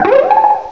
cry_not_timburr.aif